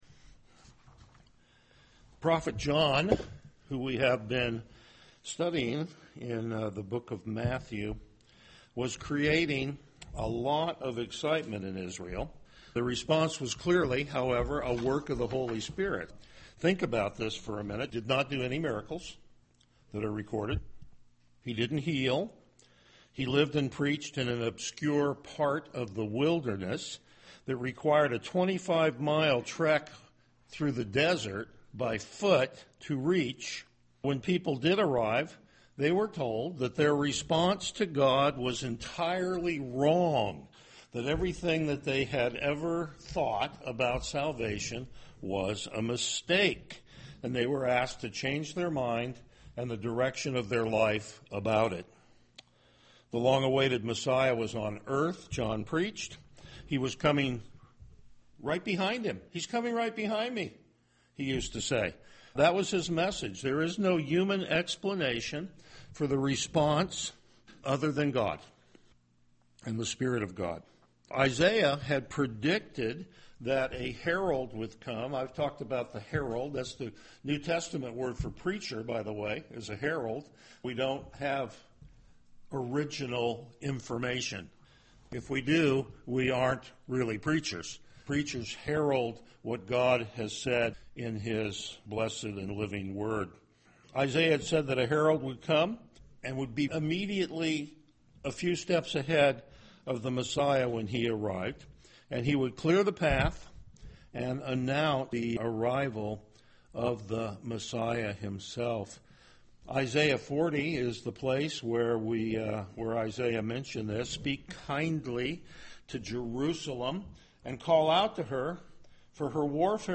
Passage: Matthew 3:7-12 Service Type: Morning Worship
Verse By Verse Exposition